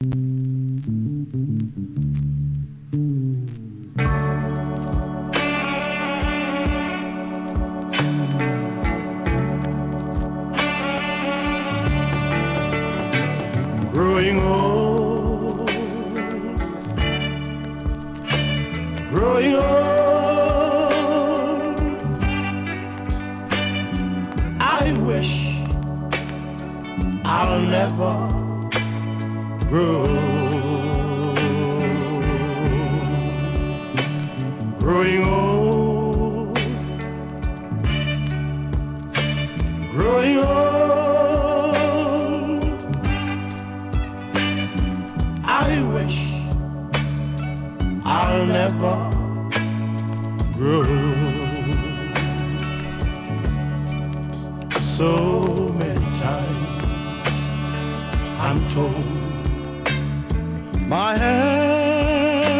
(STEREO)